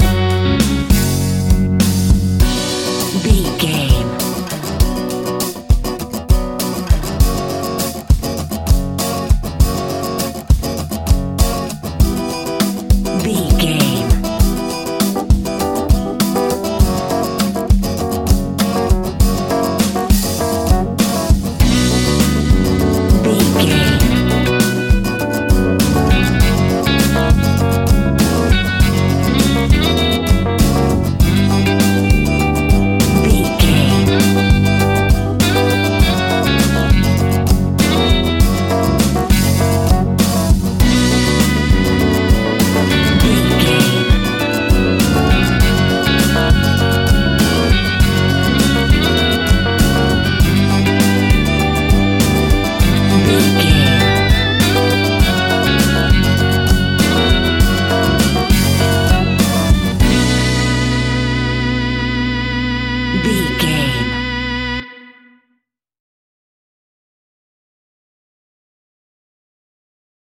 Aeolian/Minor
flamenco
instrumentals
maracas
percussion spanish guitar